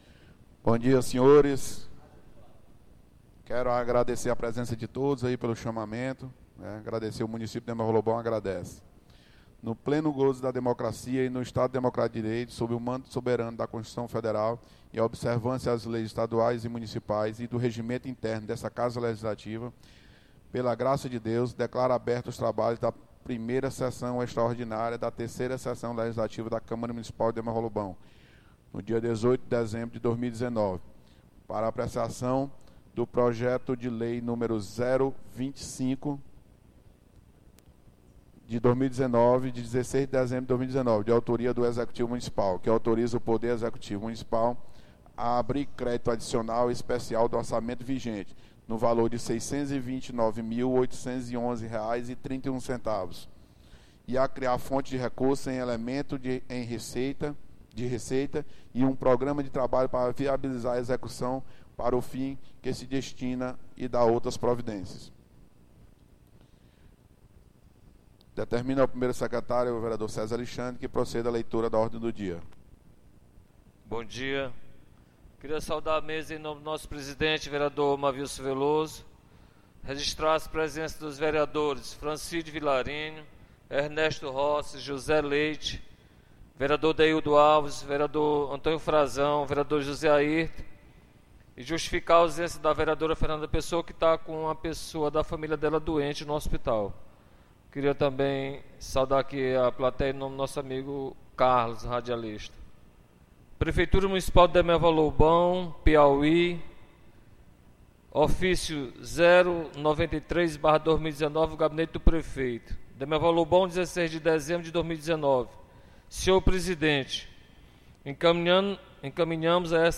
1ª Sessão Extraordinária 18 de Dezembro